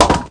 bowling02.mp3